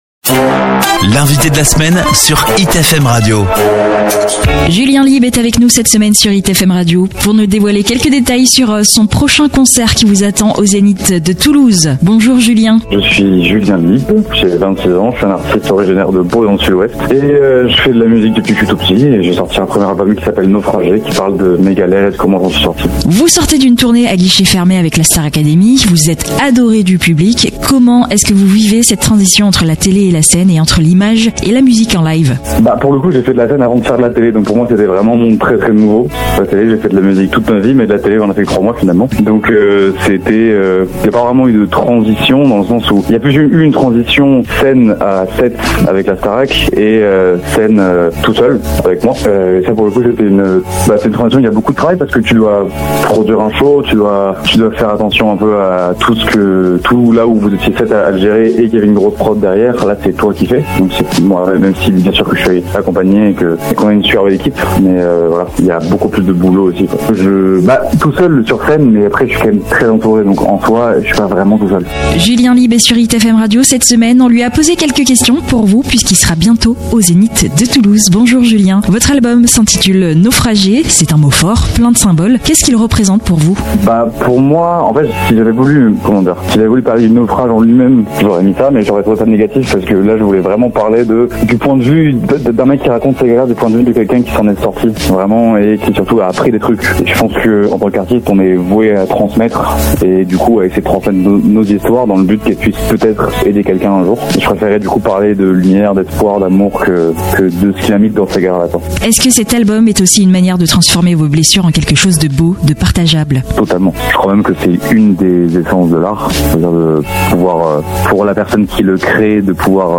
Une rencontre touchante et inspirée, à l’image d’un artiste en plein envol, qui transforme chaque chanson en histoire à vivre.